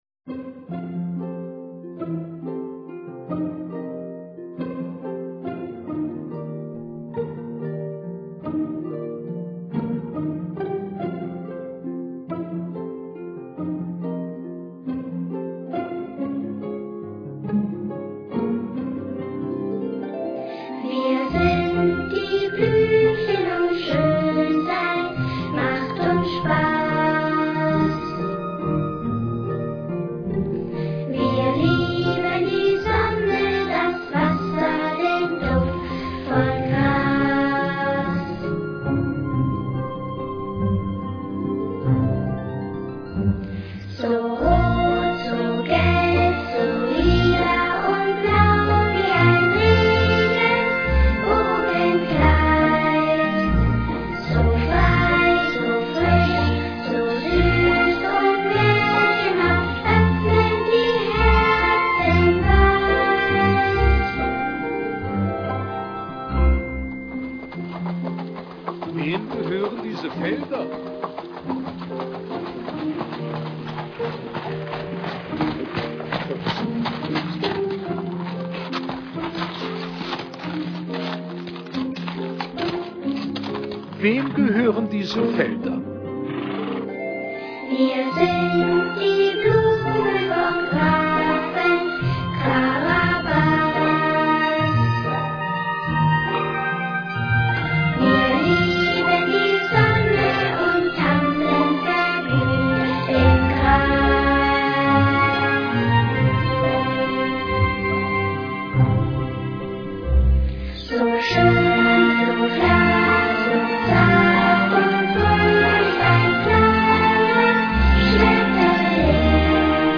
Gesungen von einigen der tanzenden Kinder.